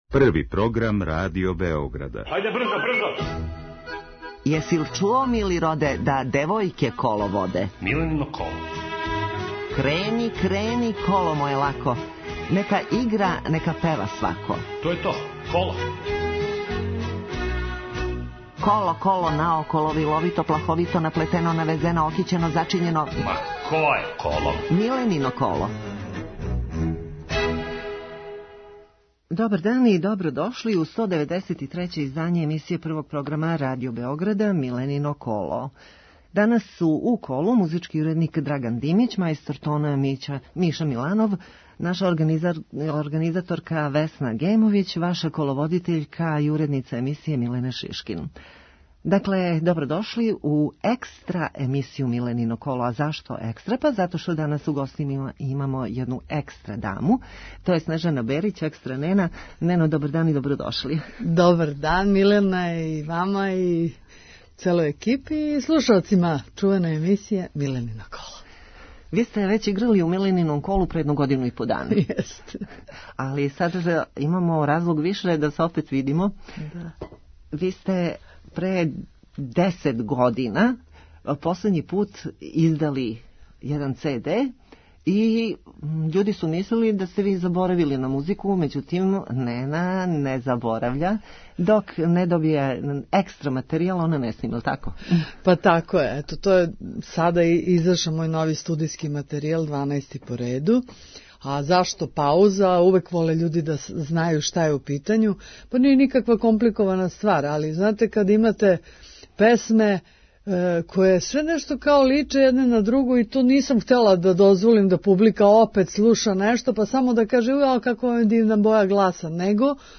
Емисија се емитује недељом од 11.05 до 12.00 о народној музици, искључиво са гостима који су на било који начин везани за народну музику, било изворну, било новокомпоновану (певачи, композитори, текстописци, музичари...). Разговор са гостом забавног карактера - анегдоте из професионалног живота, најдража песма, највећи успех, хоби и томе слично.